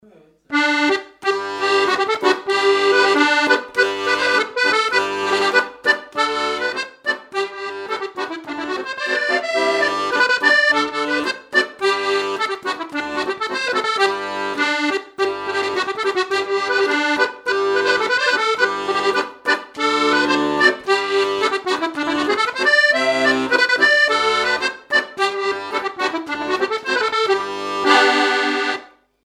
danse : scottich sept pas
Pièce musicale inédite